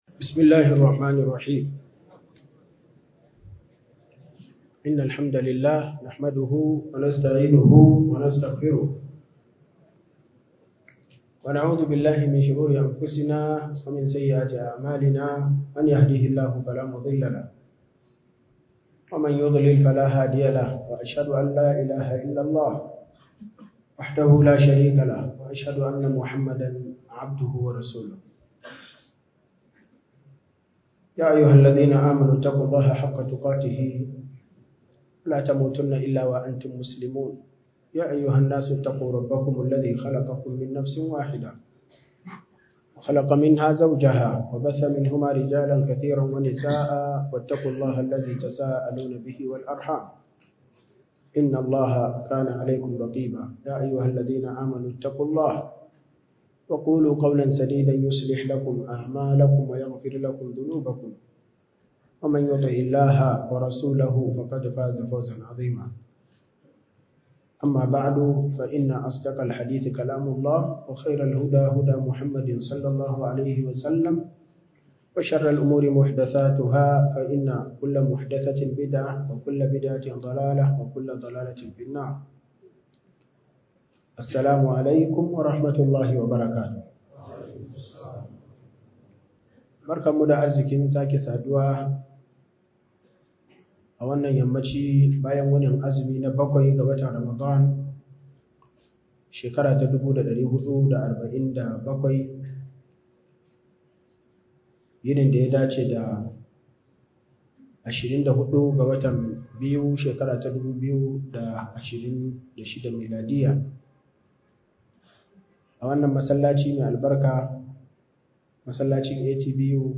Ramadan Tafsir